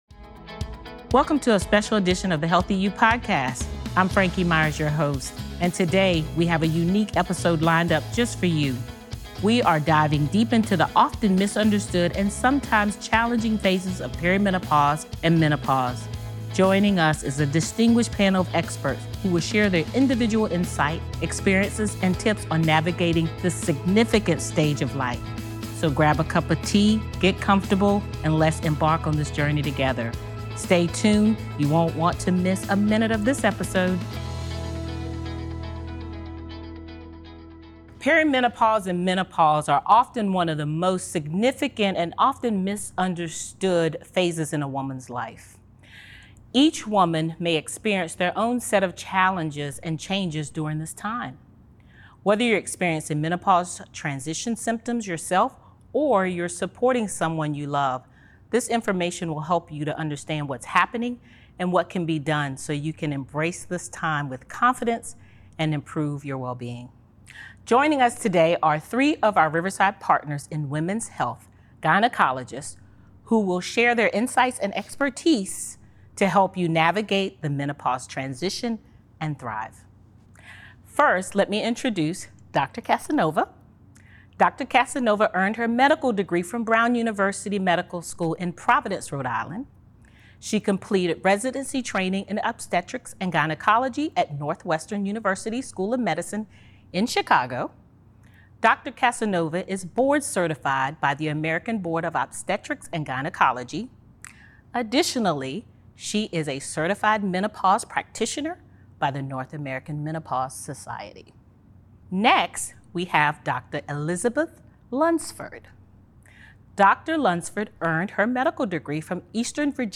Navigate perimenopause and menopause more comfortably with three of Riverside Partners in Women's Health gynecologists.